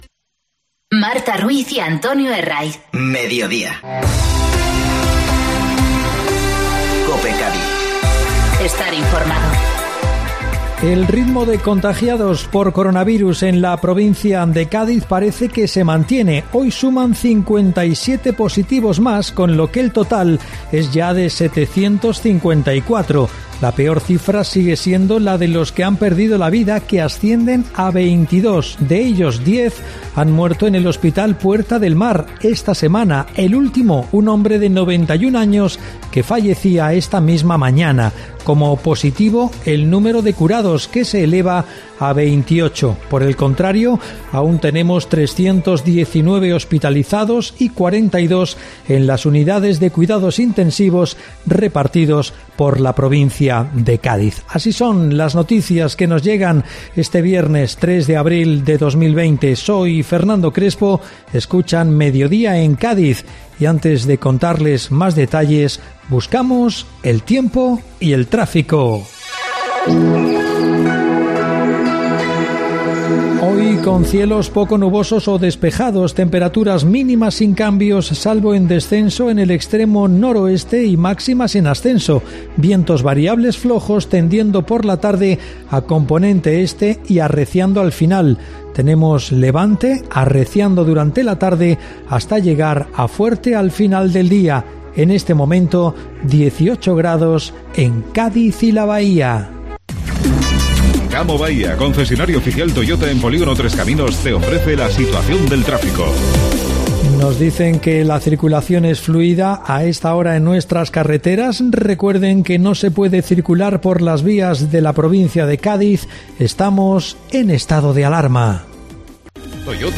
Informativo Mediodía COPE Cádiz (3-4-2020)